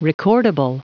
Prononciation du mot recordable en anglais (fichier audio)
Prononciation du mot : recordable